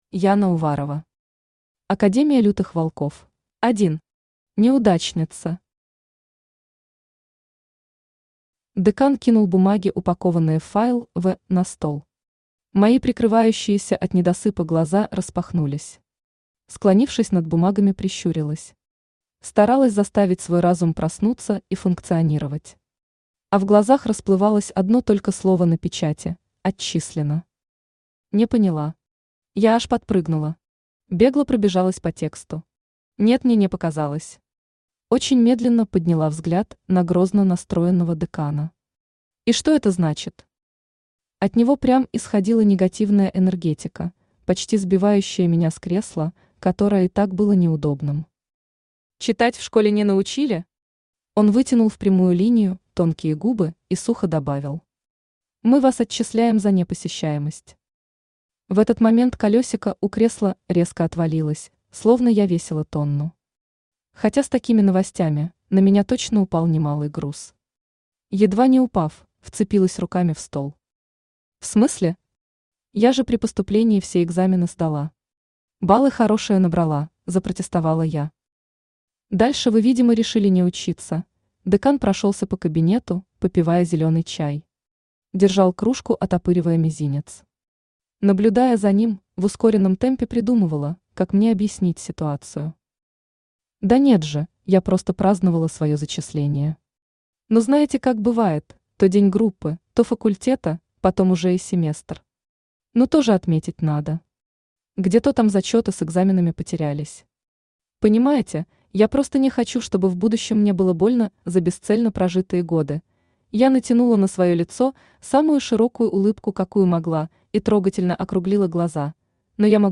Аудиокнига Академия лютых волков | Библиотека аудиокниг
Aудиокнига Академия лютых волков Автор Яна Уварова Читает аудиокнигу Авточтец ЛитРес.